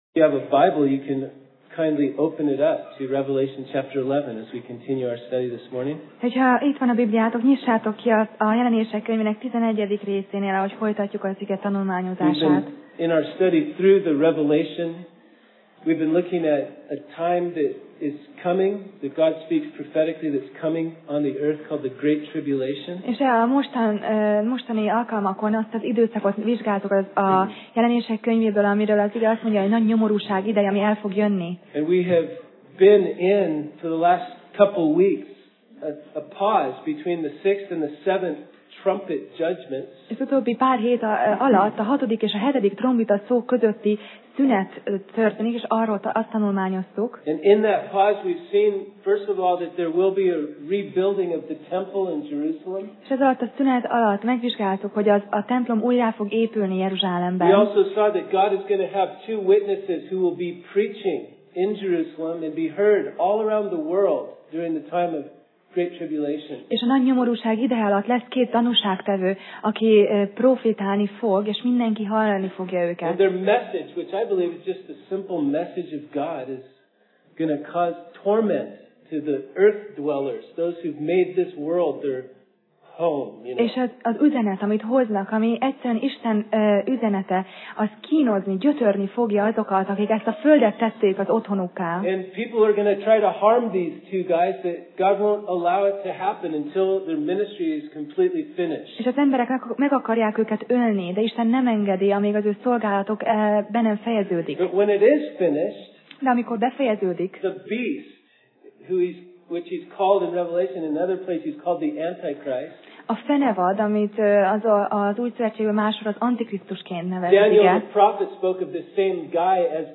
Passage: Jelenések (Revelation) 11:13-19 Alkalom: Vasárnap Reggel